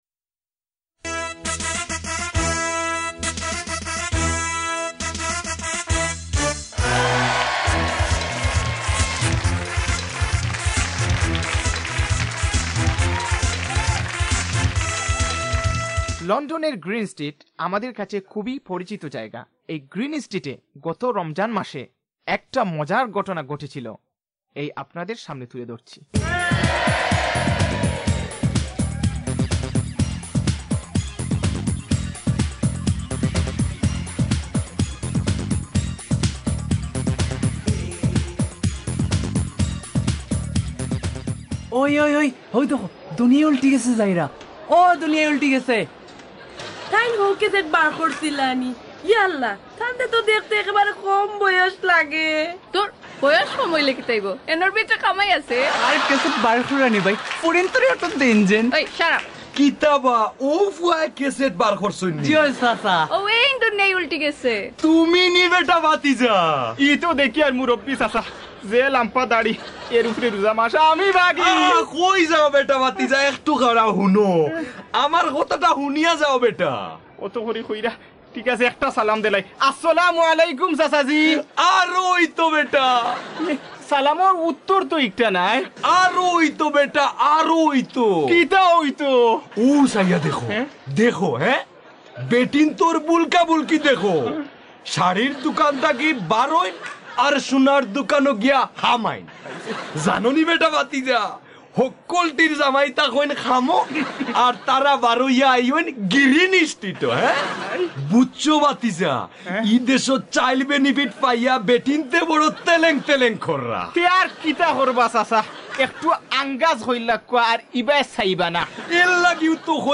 Shovab Part 2 – Comedy